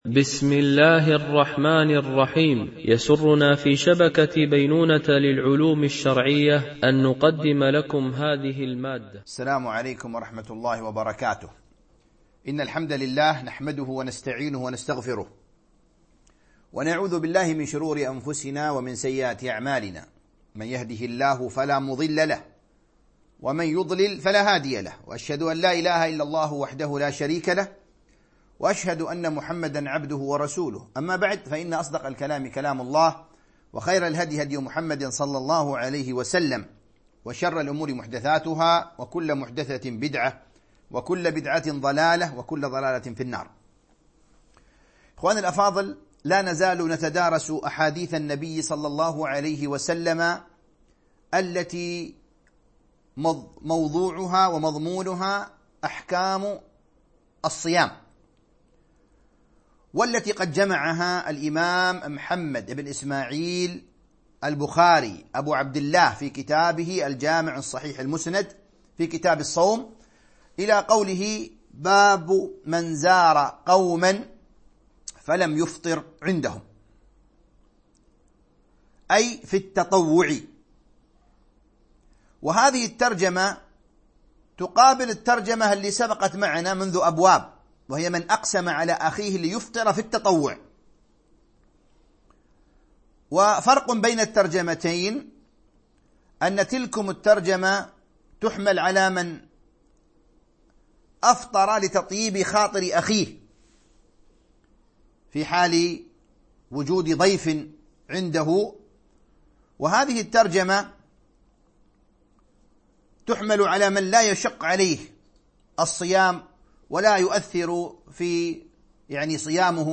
التعليق على كتاب الصيام من صحيح البخاري ـ الدرس 16